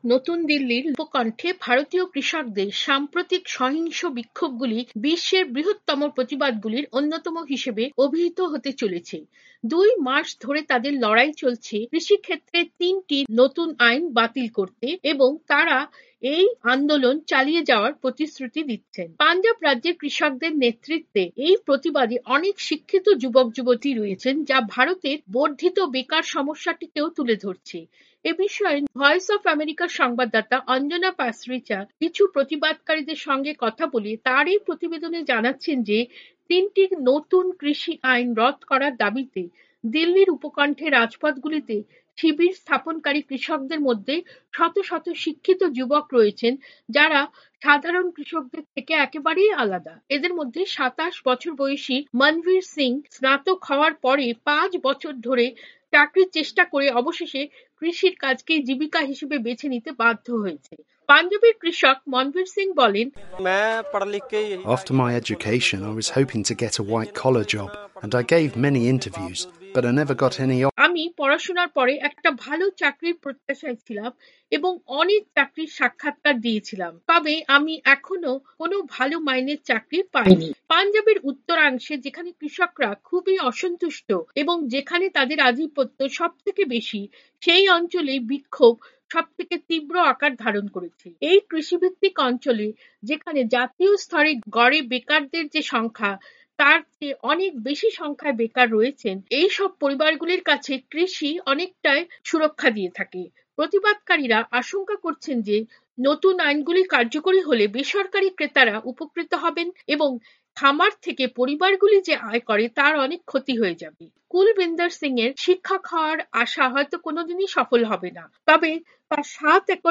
প্রতিবেদন পড়ে শোনাচ্ছেন